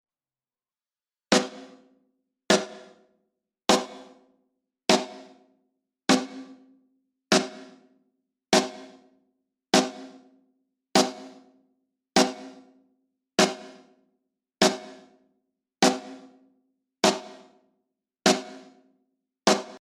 je suis en train de restaurer un vieux enregistrement live que j'avais fait lors d'un concert de mon groupe...
sur ce bus j'insère d'abord un noise-gate pour extraire la caisse claire (un eq en side-chain-key peut être extrêmement utile); puis j'égalise le son et le traite avec un transient-designer puis je mets une petite dose de compression et y ajoute de la reverbe Room.... cela donne donc ça :